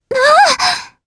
Frey-Vox_Happy4_jp.wav